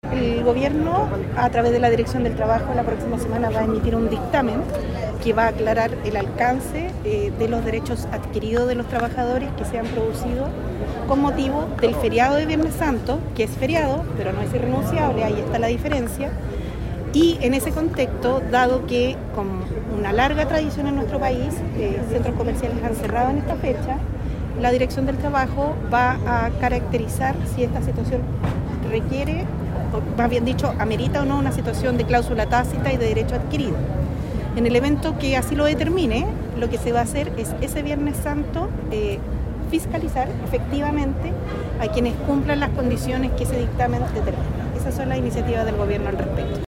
Este jueves se realizó un encuentro ciudadano en Concepción, en el contexto de la reciente promulgación de la Ley de Reforma de Pensiones, con la participación de la ministra del Trabajo y Previsión Social, Jeannette Jara, junto a otras figuras políticas de la región.